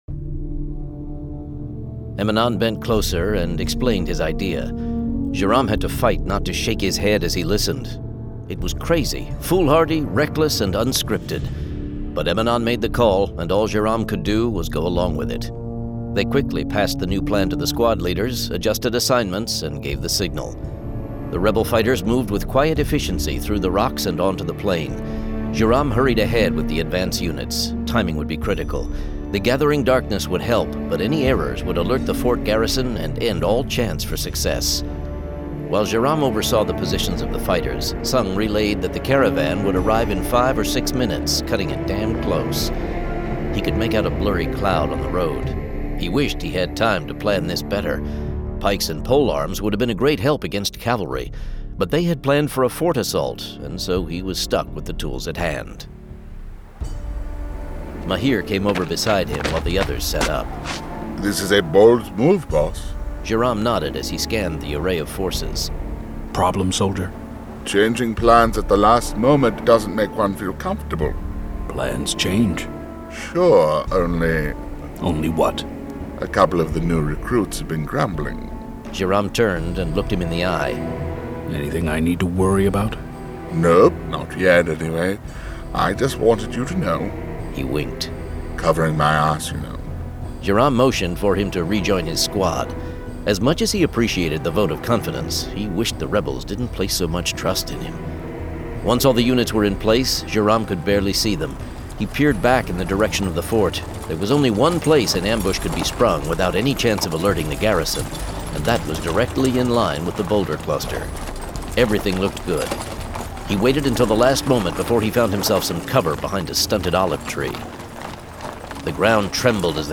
Full Cast. Cinematic Music. Sound Effects.
[Dramatized Adaptation]
Genre: Fantasy